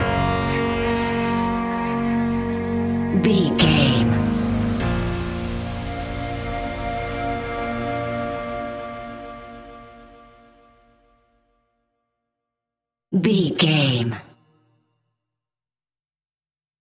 In-crescendo
Thriller
Aeolian/Minor
ominous
dark
eerie
creepy
horror music
Horror Pads
horror piano
Horror Synths